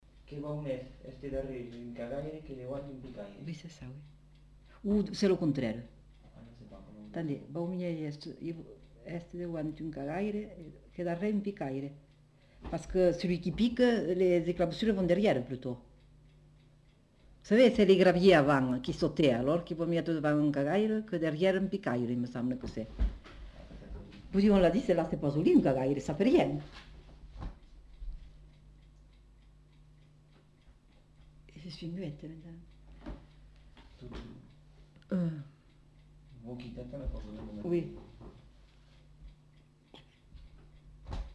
Lieu : Cathervielle
Genre : forme brève
Effectif : 1
Type de voix : voix de femme
Production du son : récité
Classification : proverbe-dicton